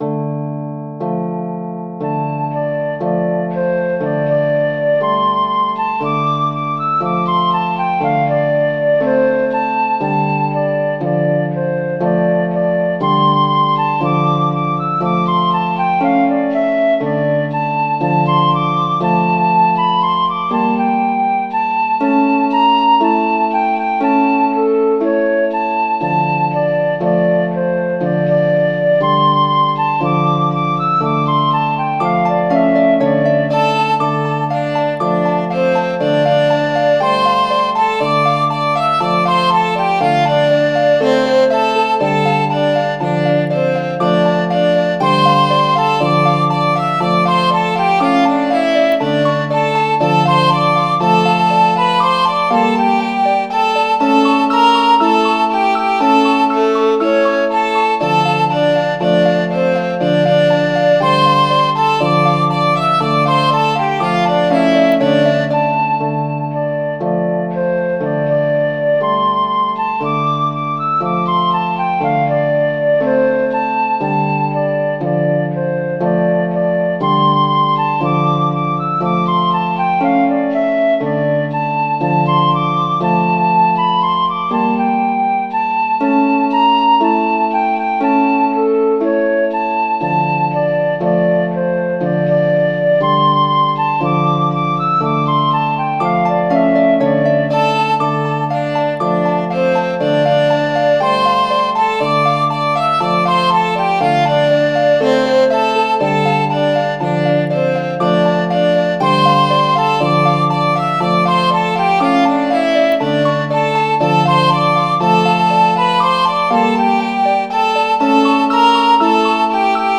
Midi File, Lyrics and Information to The Dying Redcoat